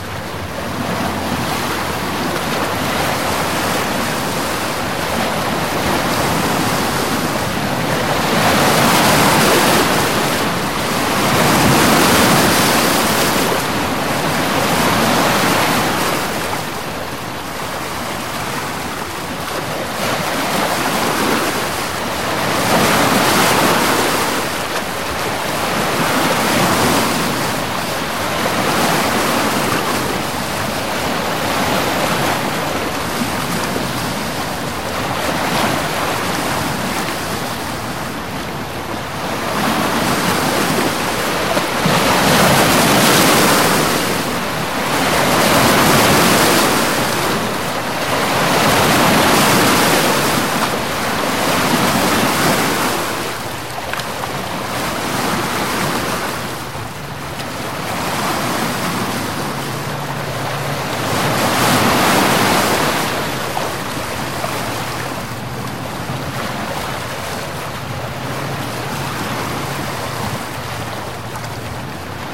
Beach
beach.mp3